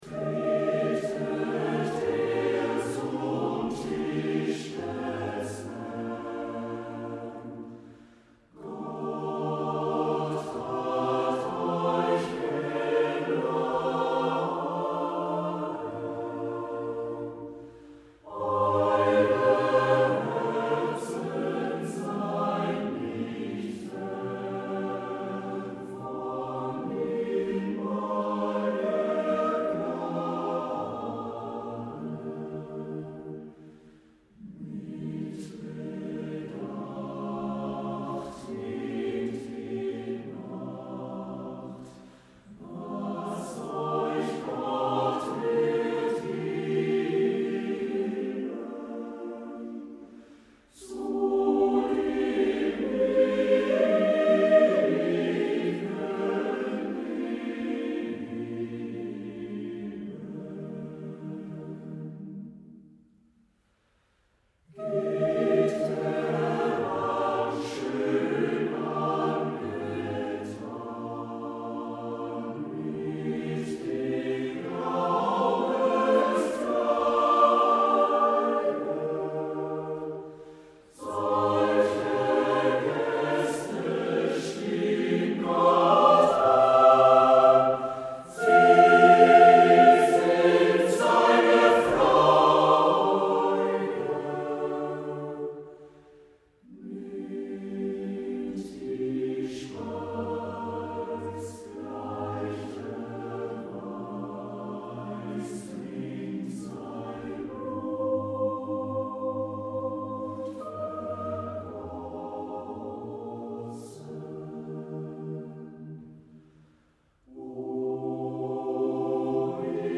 Chorproben MIDI-Files 497 midi files